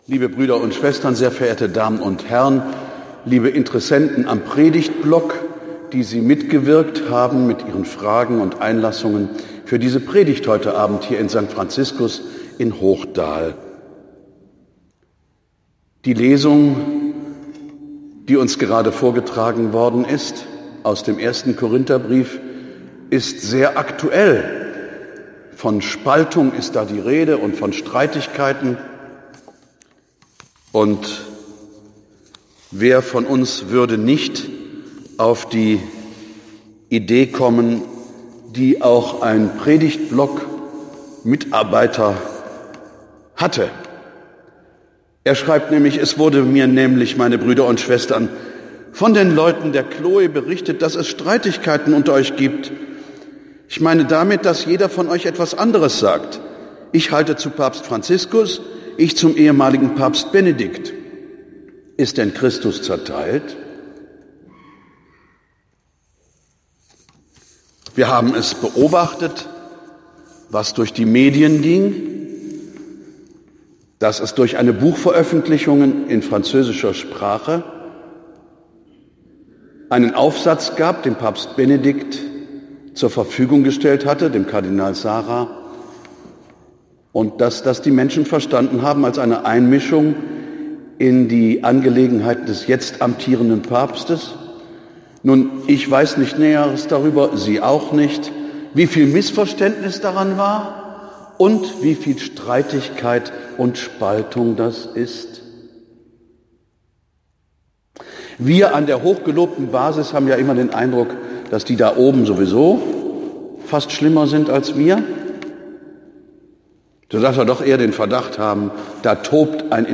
Predigt am 26. Januar 18h Hl. Geist
Die Predigt am 26. Januar um 18h in St. Franziskus als Audio: